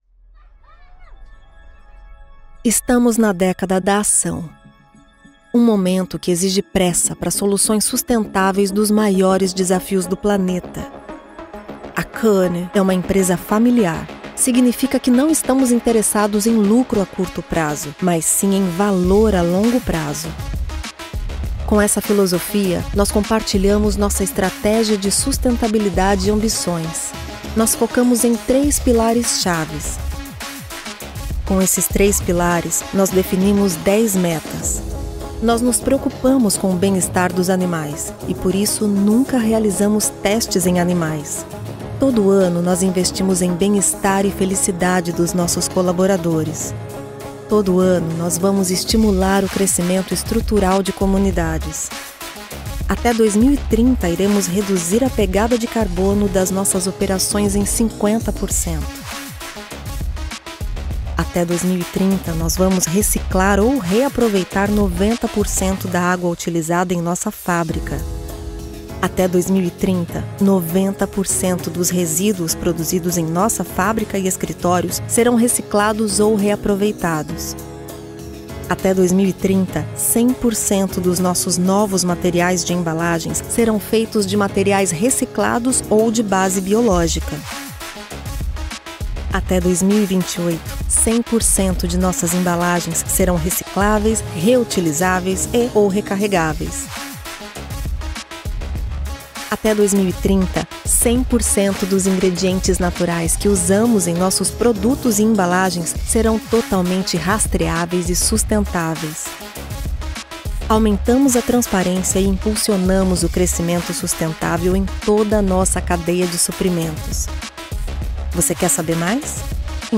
Female
Approachable, Assured, Bright, Cheeky, Conversational, Corporate, Deep, Friendly, Natural, Posh, Smooth, Warm, Young
My accent is considered neutral, with a soft memory of São Paulo prosody.
Warm, youthful, and highly professional, my voice delivers a natural and conversational performance with a neutral accent.
Microphone: AKG P220